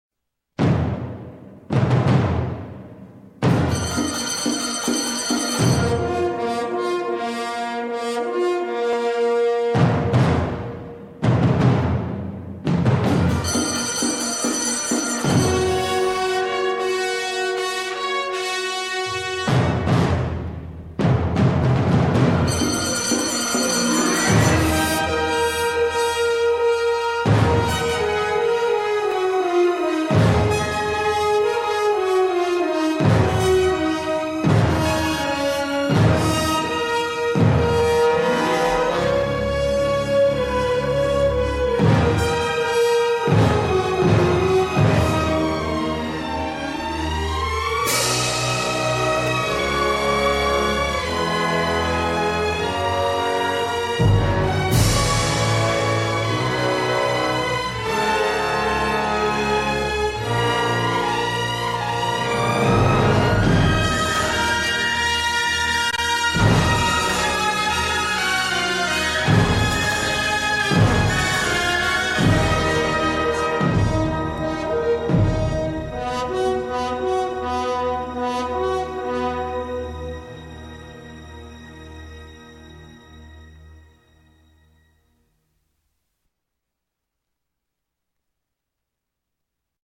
épica y solemne partitura de armonías marroquíes